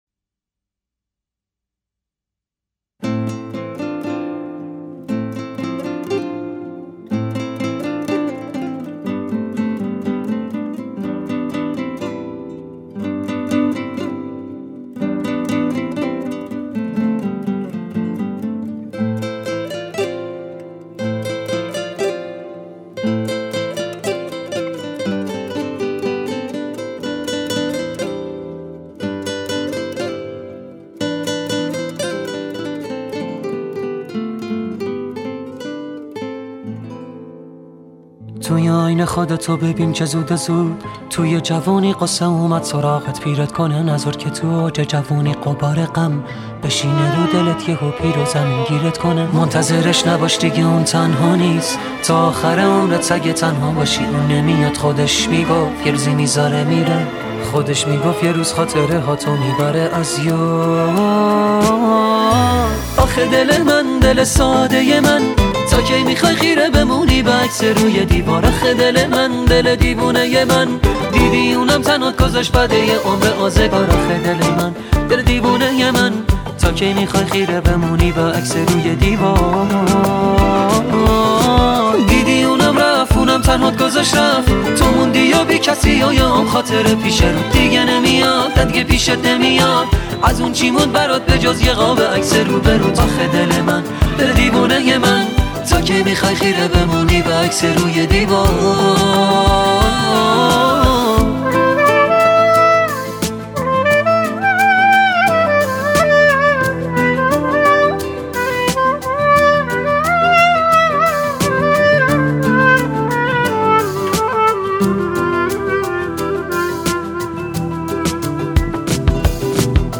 آهنگ عاشقانه